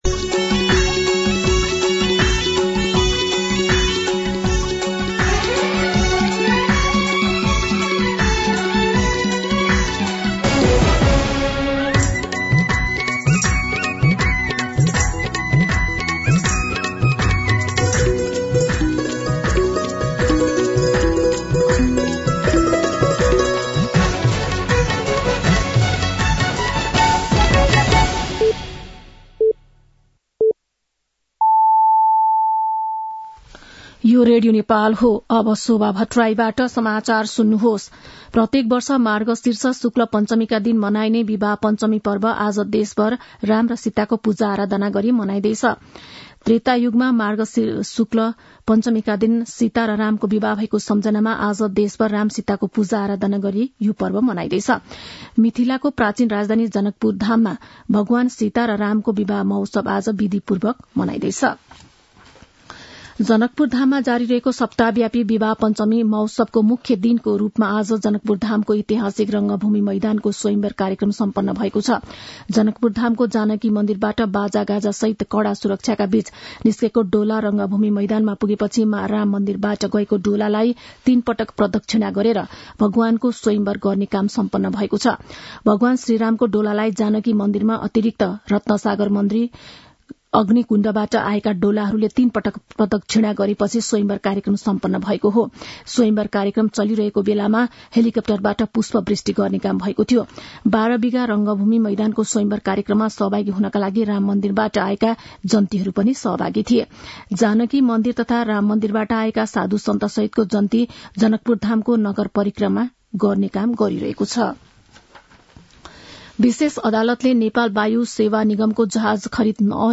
दिउँसो ४ बजेको नेपाली समाचार : २२ मंसिर , २०८१